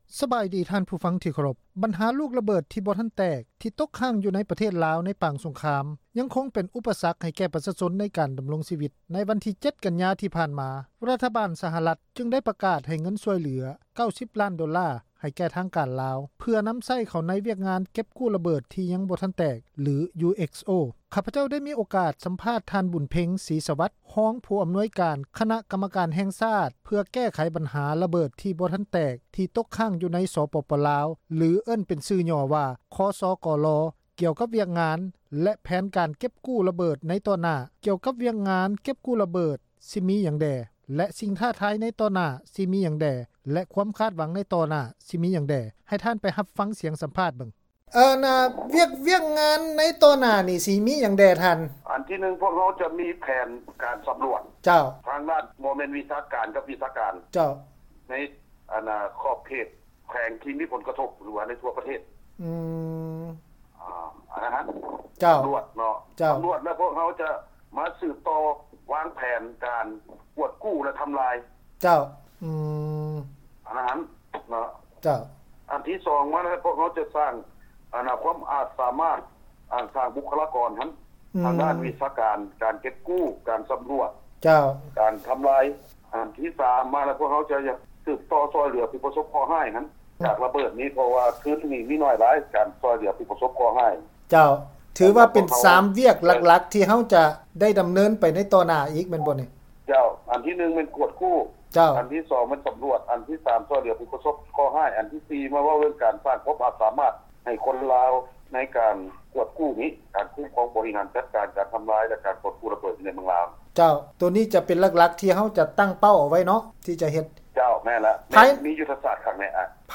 ສໍາພາດທາງການລາວ UXO
F-uxo ສຳພາດ ບຸນເພັງ ສີສະຫວັດ ຮອງ ຜູ້ອຳນວຍການ ຄນະກັມມະການ ແຫ່ງຊາດ ເພື່ອແກ້ໄຂບັນຫາ ຣະເບີດທີ່ບໍ່ທັນແຕກ ທີ່ຕົກຄ້າງຢູ່ໃນ ສປປ ລາວ